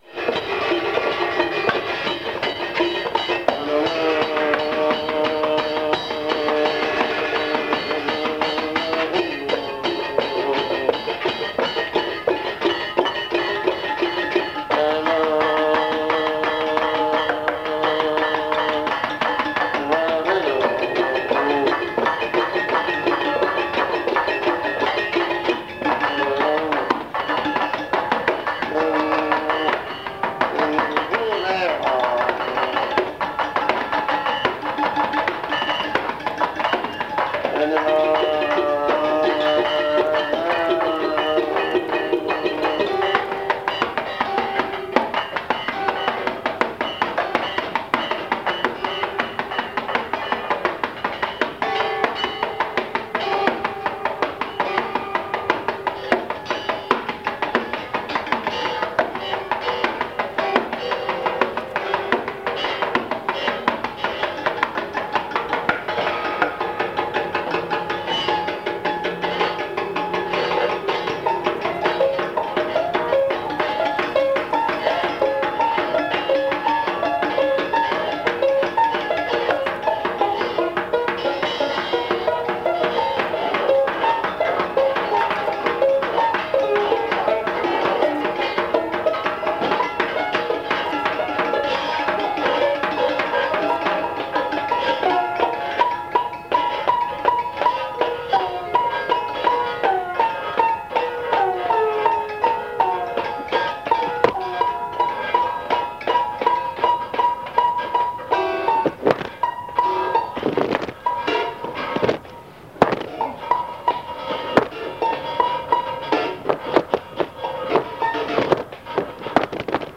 -Içi il y a des extraits des enregistrements réalisé au dictaphone lors de ma tournée en argentine et au chili
ou je joue mes chansons ou j improvise ou que je compose des nouveaux trucs